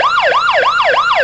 and final round buzzer sounds.
Final Round - Correct.ogg